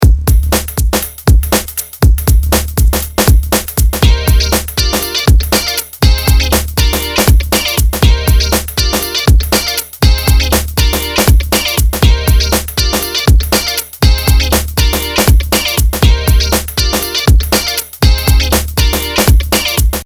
Еще одна битва лимитеров! ( Тест )
не соглашусь , по поводу фаба и инвизибла фаб ощутимо сильнее искажает , с длинной атакой и коротким релизом , в режиме трансиент приорити ну там всякие пресеты hardrock , metal ( как раз когда он не жрет атаки )